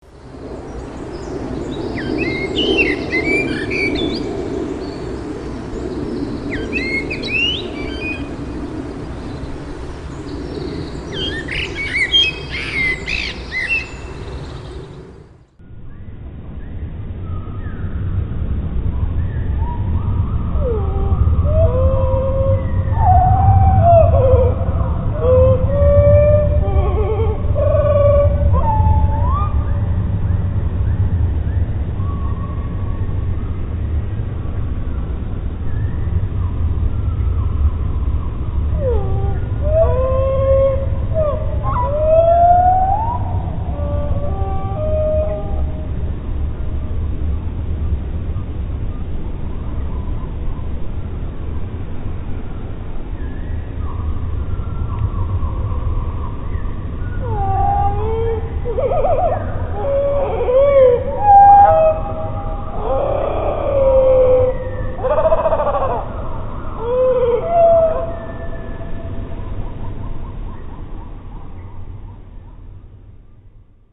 nagranie zawiera 3 piosenki pewnego kosa.
Znajduje się tam pięciodźwiękowy fragment, zagwizdany, czy może zagrany jakgdyby na innym instrumencie. Dźwięki tworzące tę melodię nie są modulowane, to jest, mają stałą wysokość i nie wibrują. Żeby łatwiej było usłyszeć tę melodyjkę, druga część nagrania jest zwolniona i obniżona.